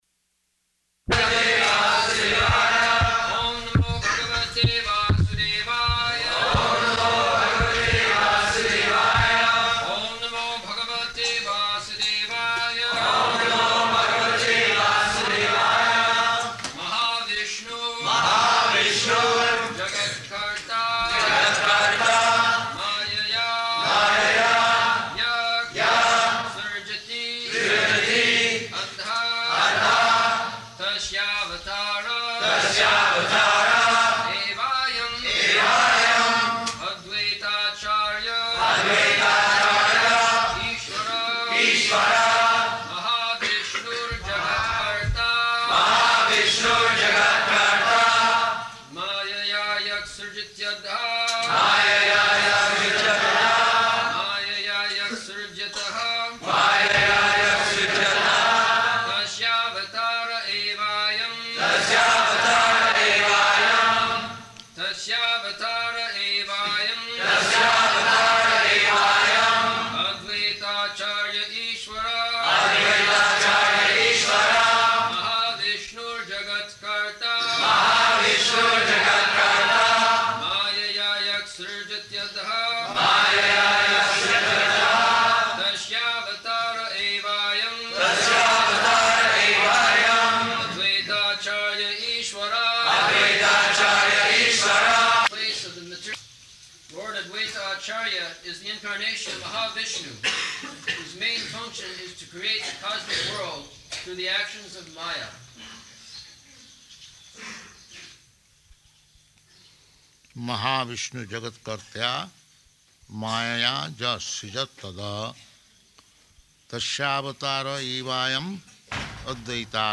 April 5th 1975 Location: Māyāpur Audio file
[devotees repeat] [leads chanting of verse, etc.] mahā-viṣṇur jagat-kartā māyayā yaḥ sṛjaty adaḥ tasyāvatāra evāyam advaitācārya īśvaraḥ [ Cc.